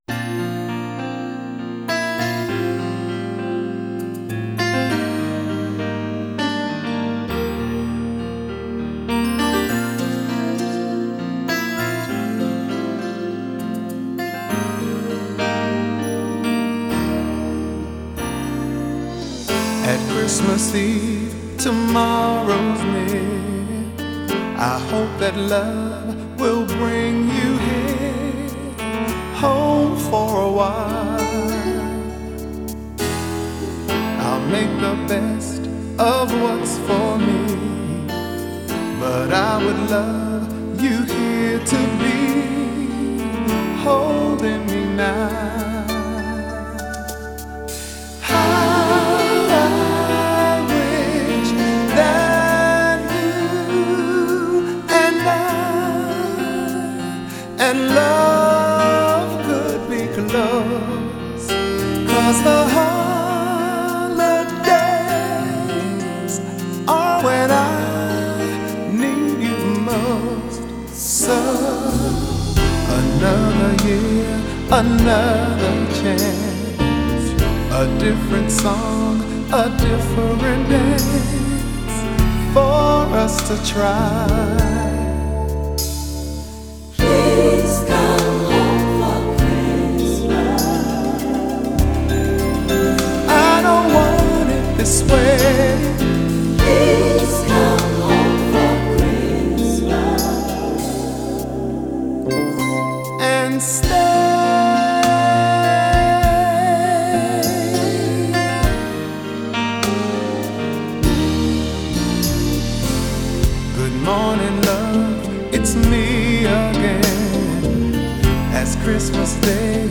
R&B crooner